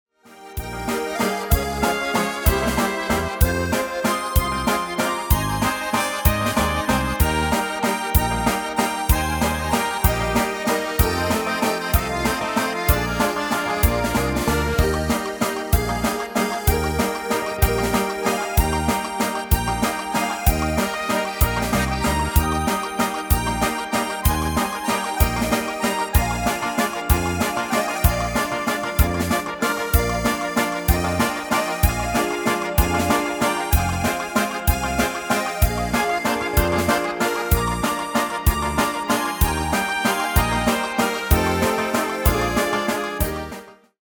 Demo/Koop midifile
Genre: Nederlands amusement / volks
Toonsoort: Eb
Demo's zijn eigen opnames van onze digitale arrangementen.